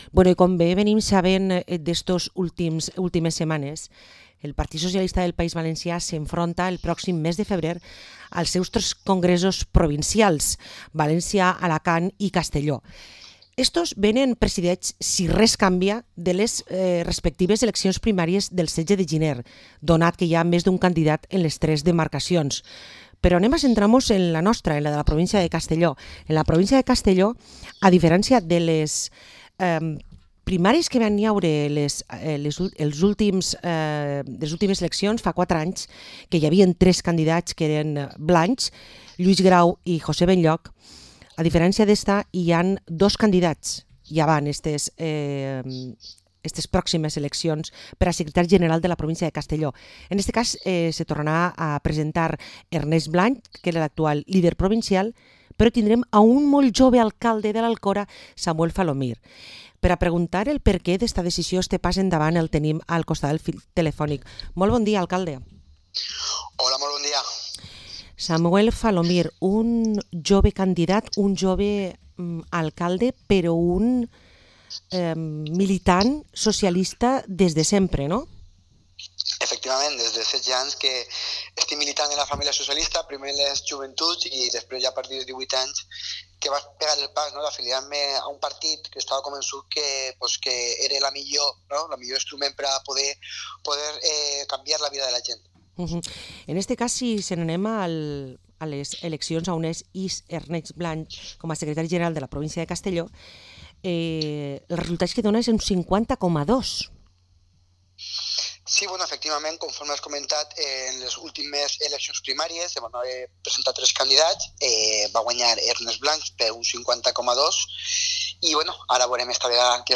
Entrevista al alcalde de l’Alcora y candidato a la secretaría general del PSPV-PSOE de la provincia de Castellón, Samuel Falomir